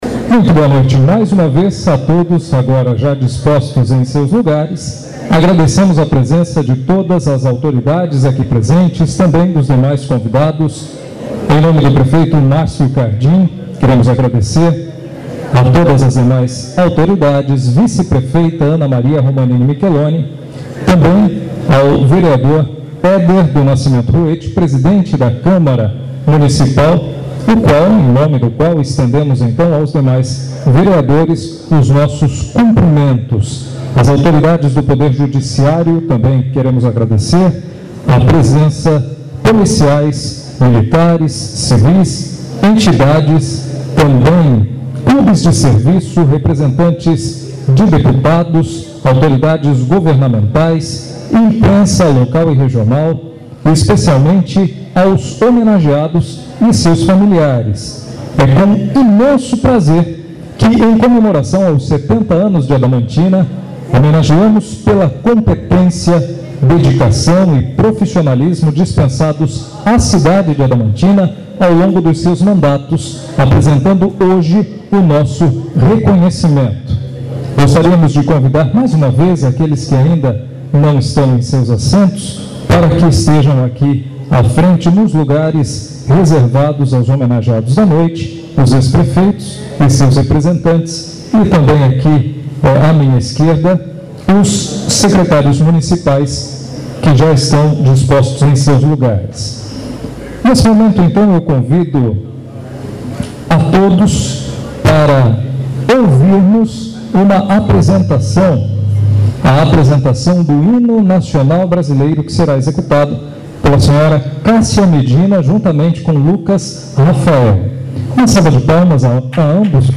Presentes autoridades legislativo, do judiciário, policiais, militares, entidades, clubes de serviço, representantes de deputados, autoridades governamentais, imprensa, homenageados e seus familiares.
O áudio da solenidade está no link logo abaixo da matéria